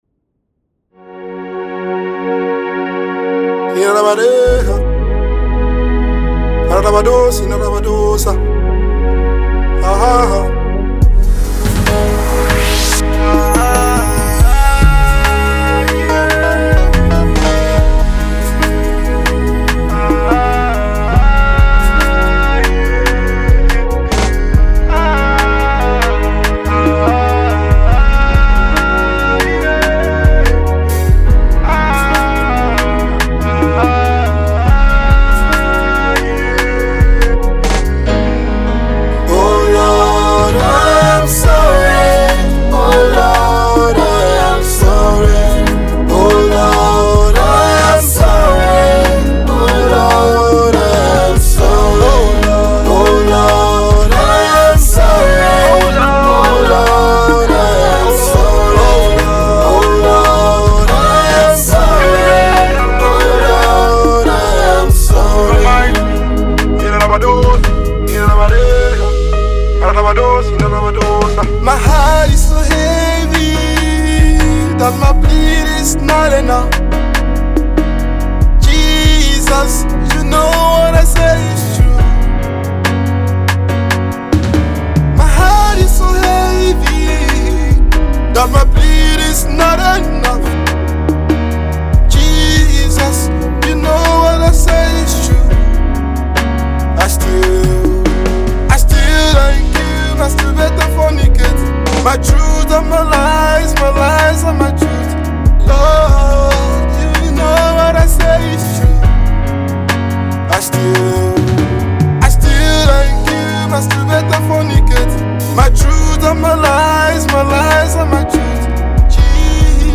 pure Afrocentric pop type of confession song